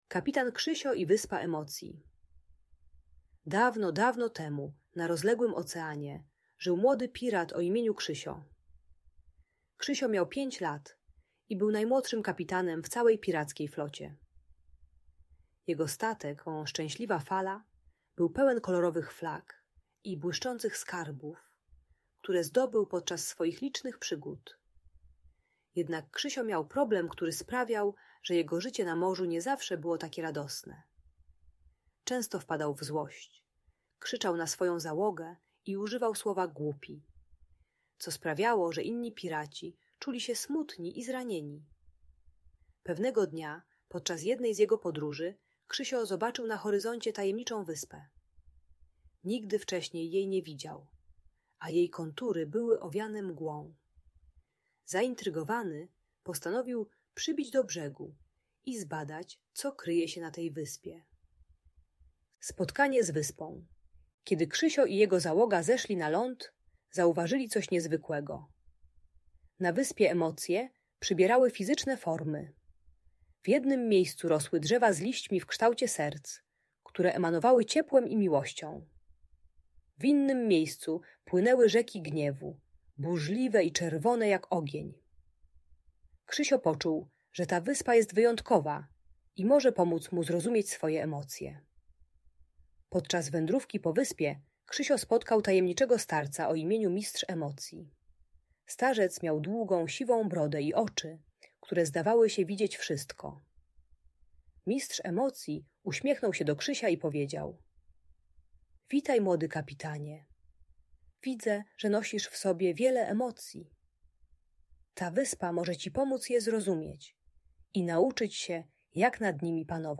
Ta audiobajka o złości i agresji uczy techniki głębokiego oddechu i spokojnego mówienia zamiast krzyku. Pomaga dziecku zrozumieć, że kontrolowanie gniewu zaczyna się od wewnętrznego spokoju.